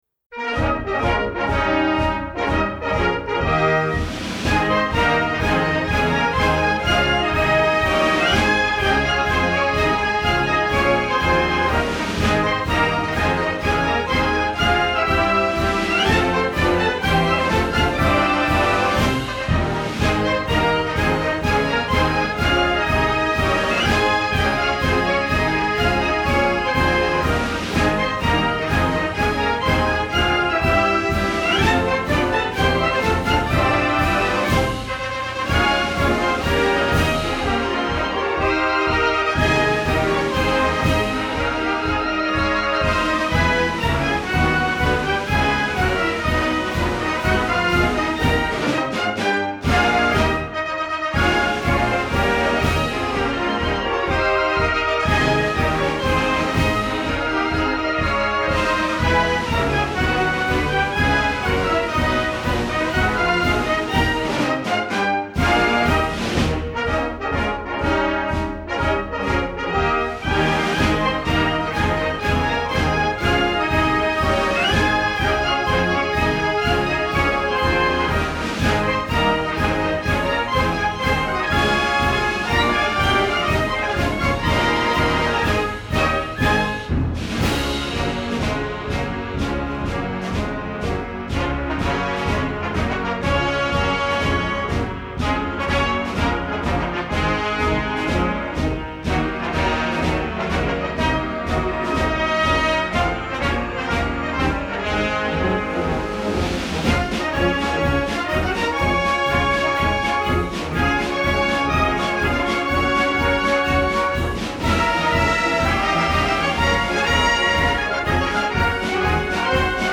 LA FEDELISSIMA � MARCIA D’ORDINANZA